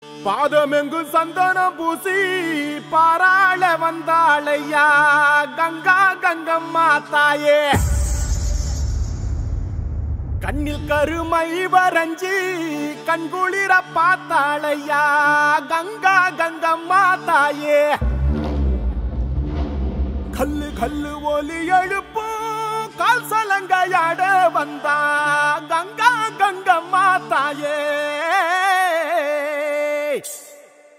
A Melodious Fusion